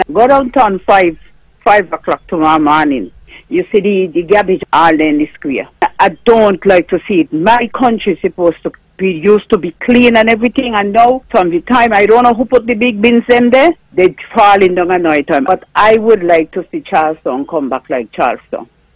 During the On the Mark Programme last Wednesday, a caller spoke on the clogging of the drains by debris around Charlestown. She also went on to mention the state of the squares: